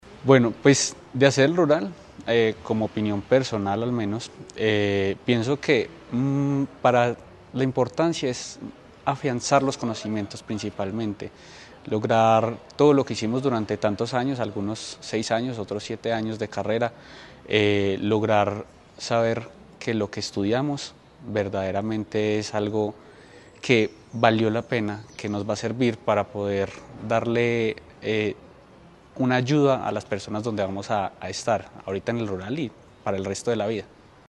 médico rural de Caldas.